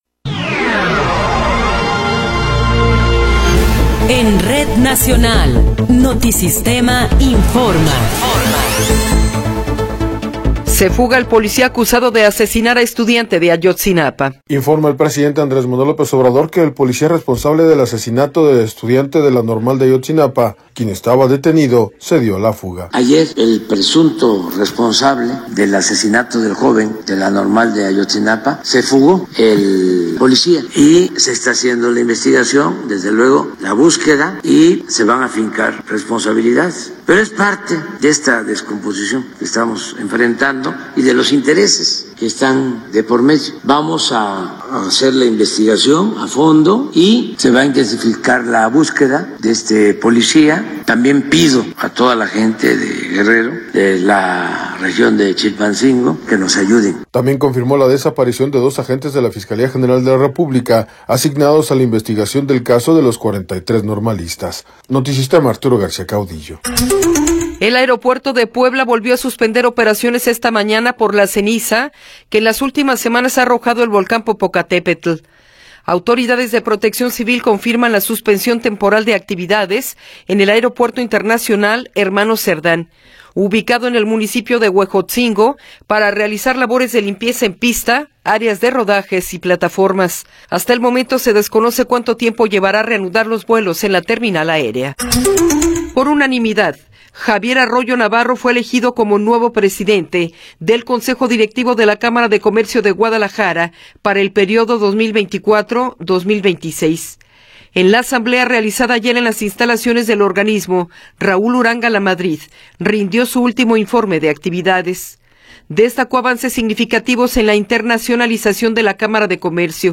Noticiero 10 hrs. – 12 de Marzo de 2024